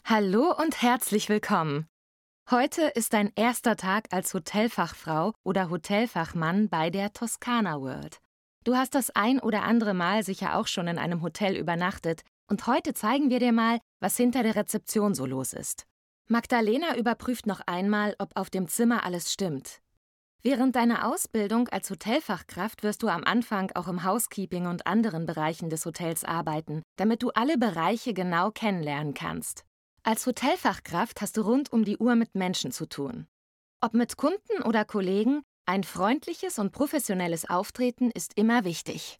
Werbesprecherin